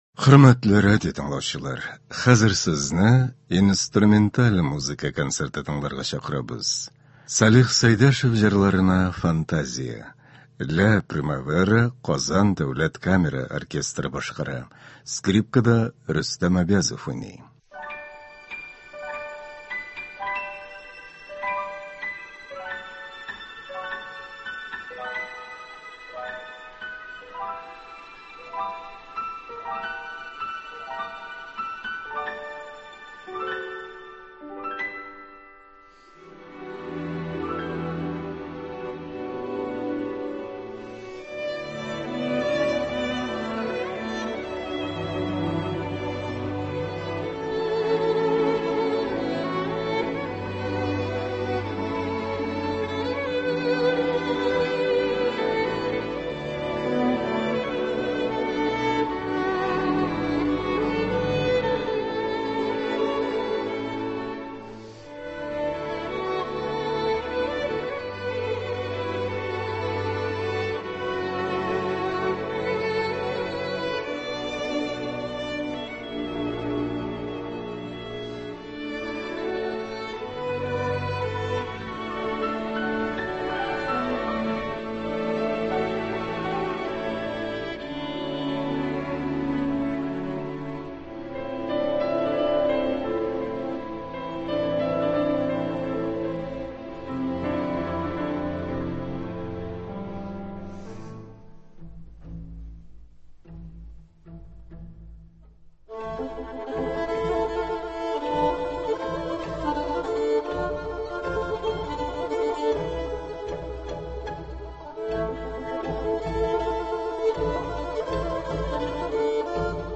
Инструменталь музыка концерты (08.09.22)